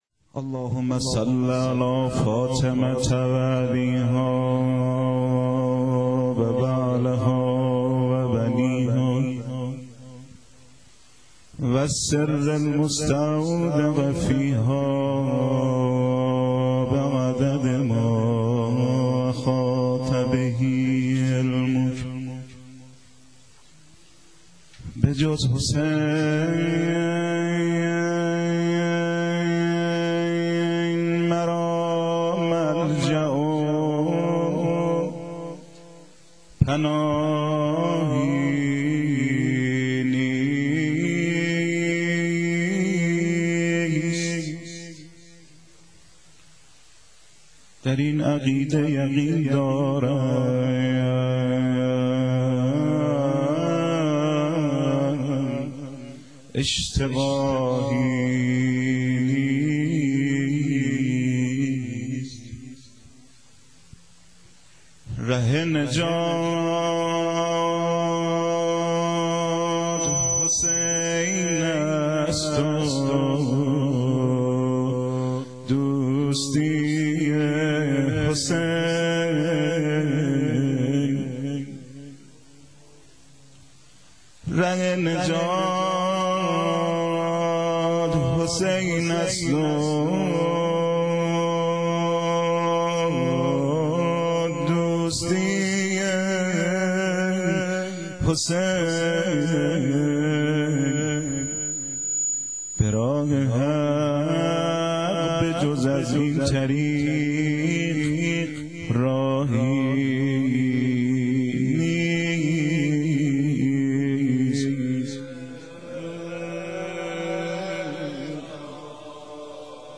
شعرخوانی بخش اول - شب هفتم محرم 1389